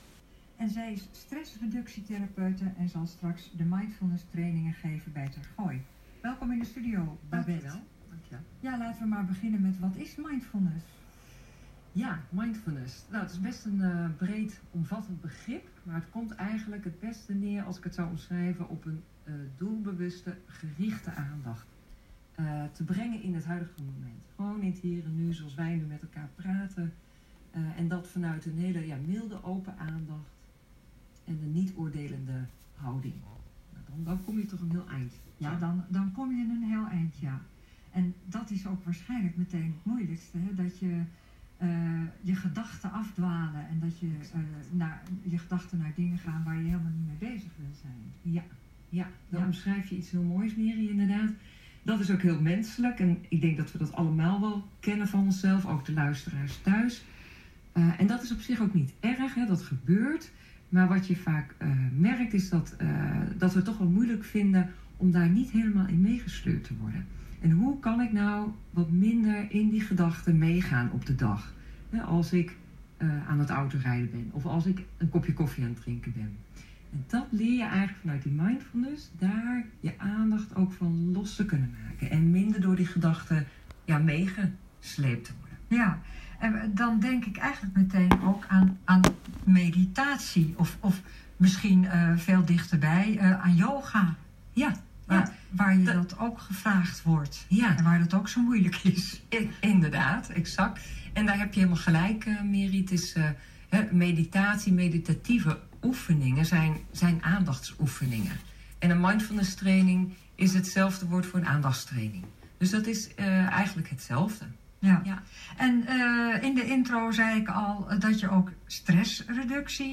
Interview met NH Radio Gooi